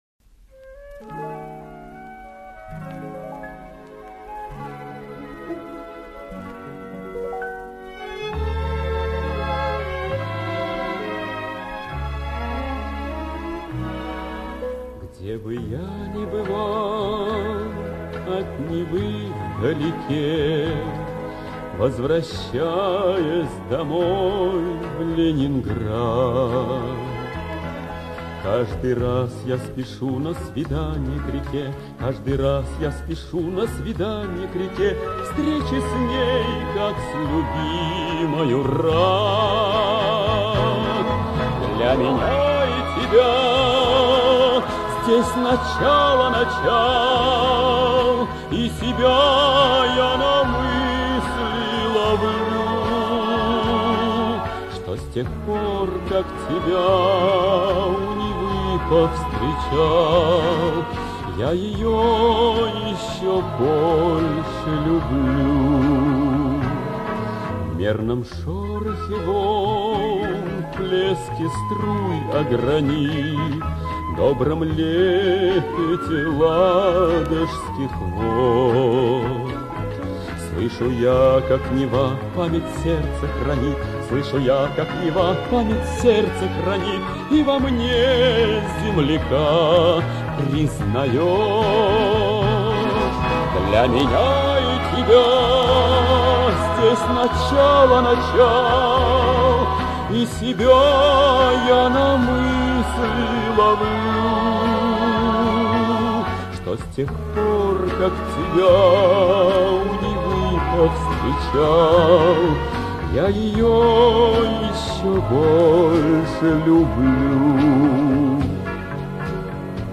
советский и российский певец (баритон).